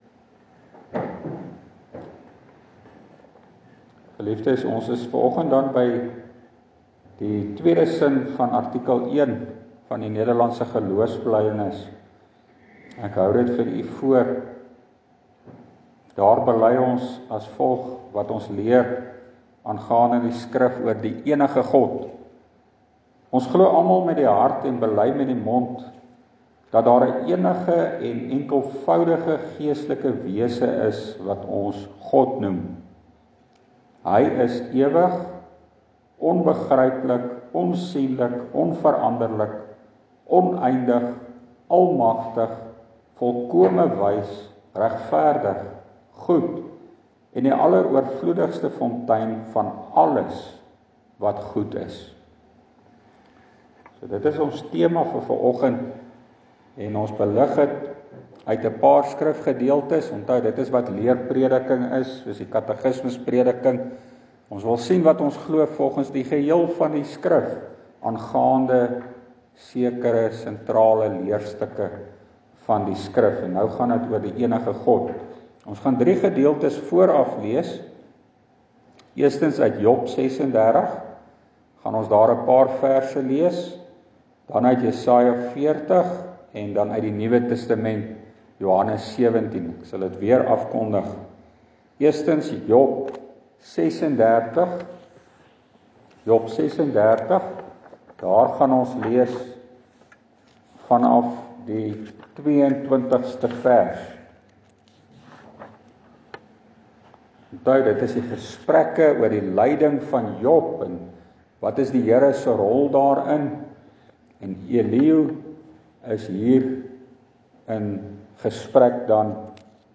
Leerprediking: Nederlandse Geloofsbelydenis artikel 1, deel 2 – Die deugde van God is ons tot troos (Job 36:26)